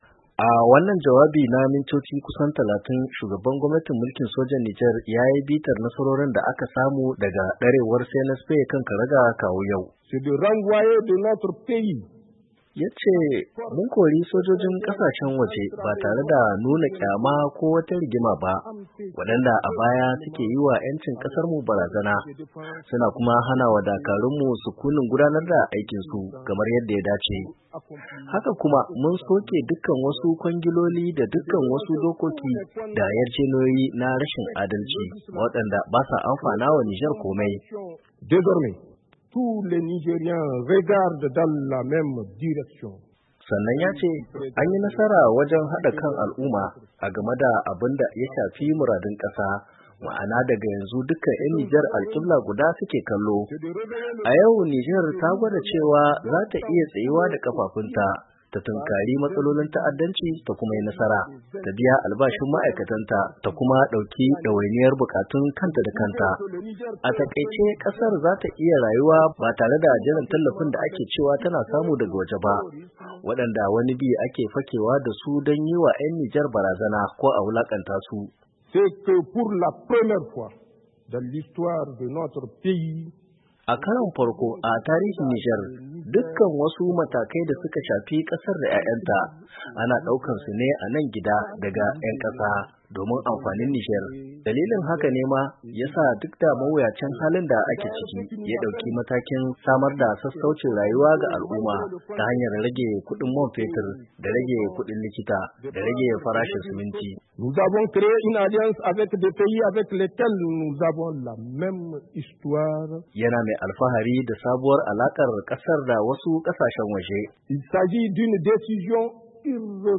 Shugaban gwamnatin mulkin sojan Jamhuryar Nijar Janar Abdourahamane Tiani ya yi jawabi ga al’umma ranar bukin samun 'yancin kai inda ya tabo mahimman batutuwan da suka shafi tafiyar kasar daga lokacin da ya kwaci madafun iko zuwa yau.
JAWABIN JANAR TIANI RANAR JAMHURIYA.